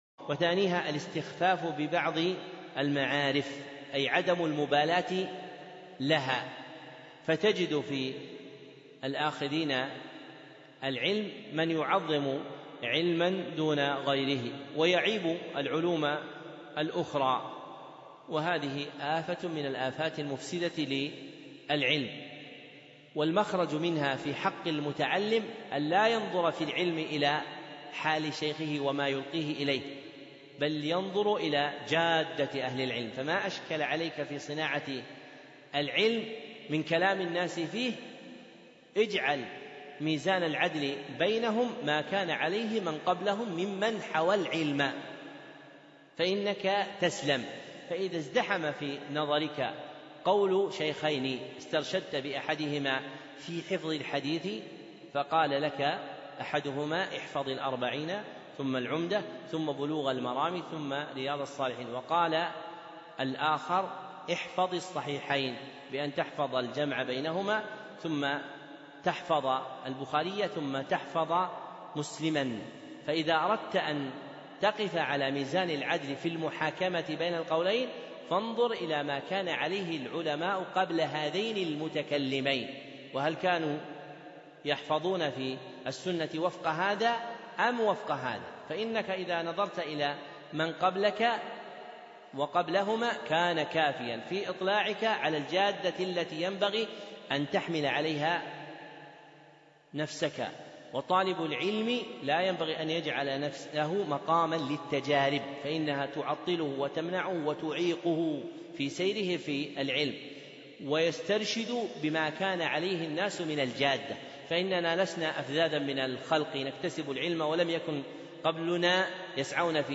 ( موعظة ) لا تكن تجربة يا طالب العلم